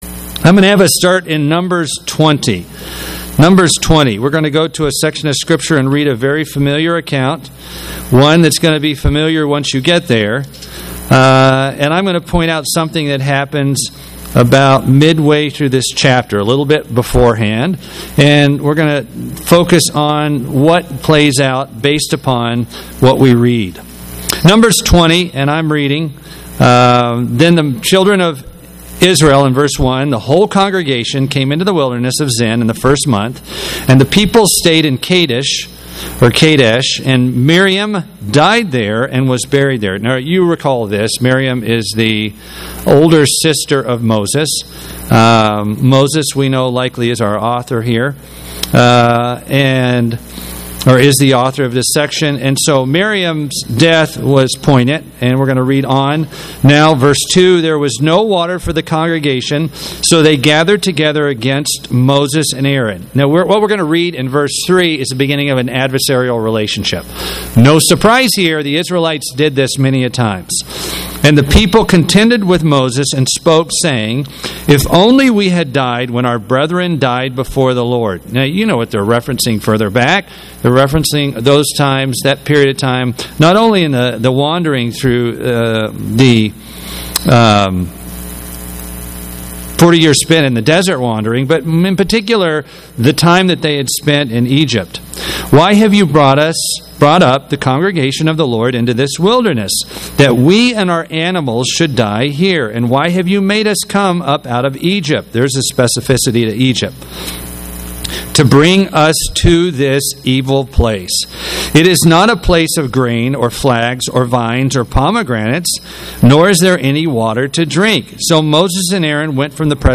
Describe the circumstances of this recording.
Given in Atlanta, GA Buford, GA